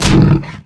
hive_wound4.wav